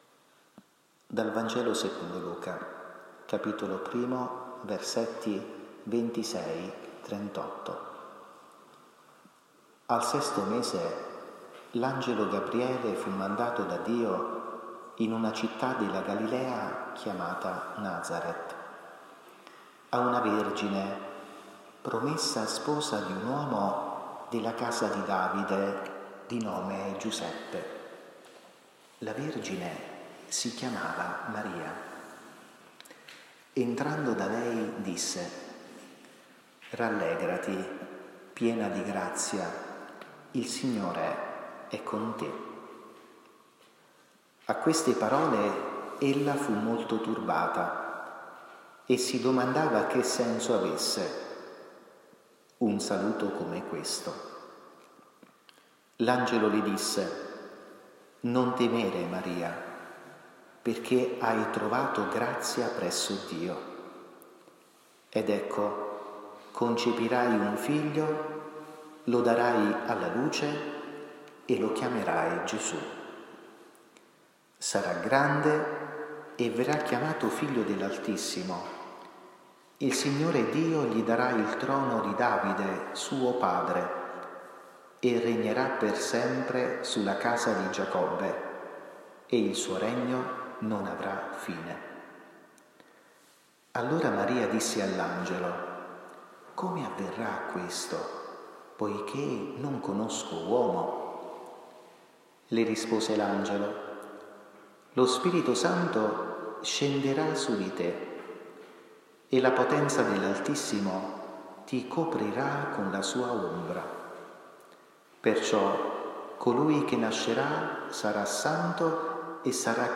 Catechesi mariana 06 maggio 2023. Maria, Discepola del Signore.